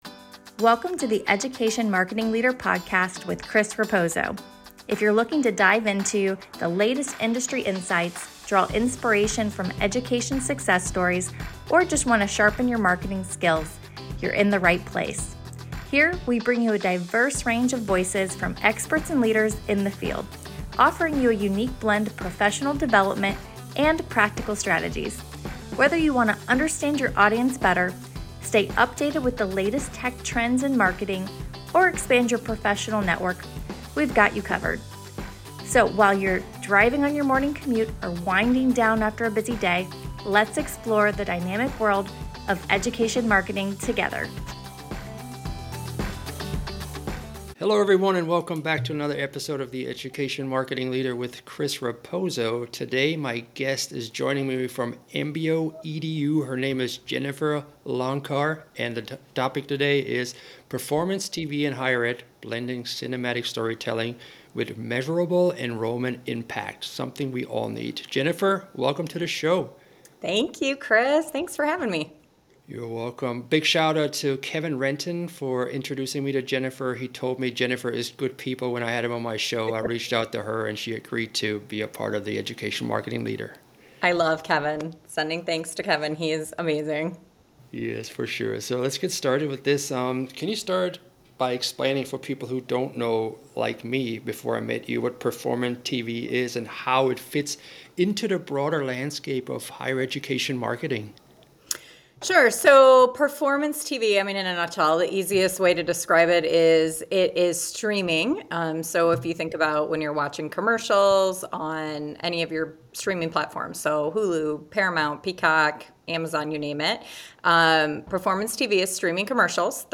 Navigating the Higher Ed Marketing Vendor Maze - A Conversation